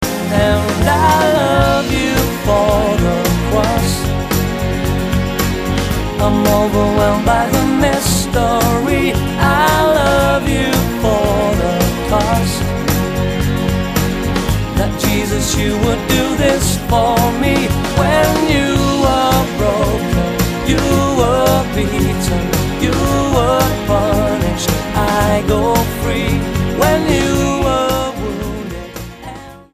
STYLE: Pop
recorded in a well-produced but fairly simplistic style